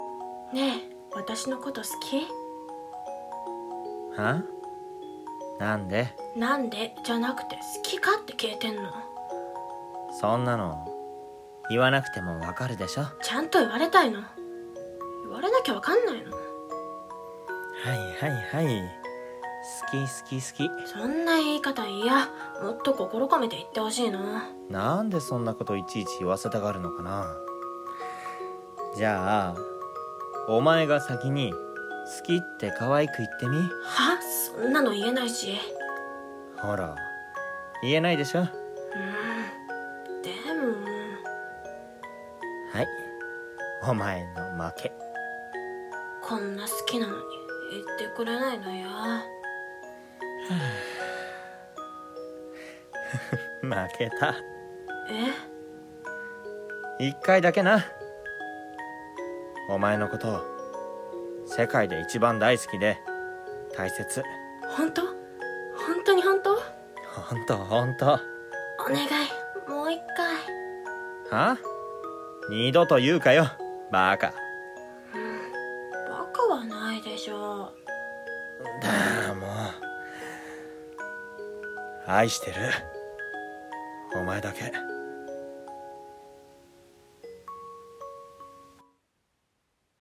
【２人声劇】好き。